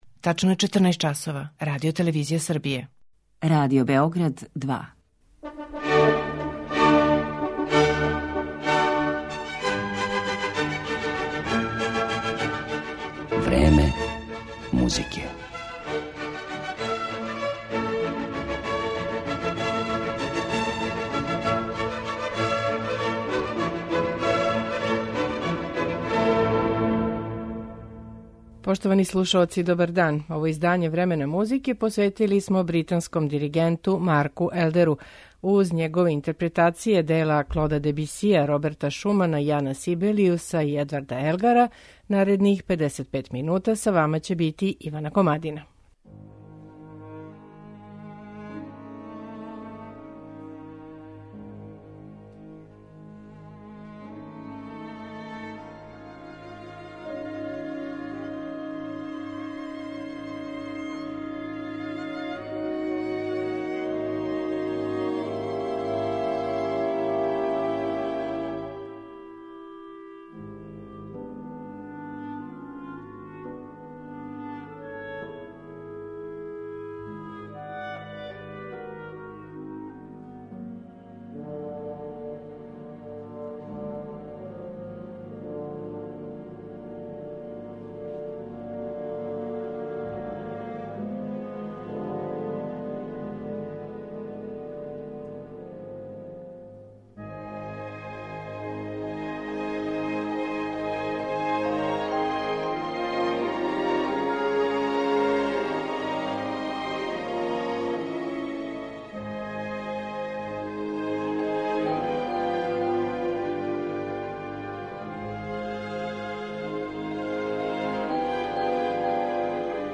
Клавирски концерт